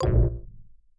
Buzz Error (13).wav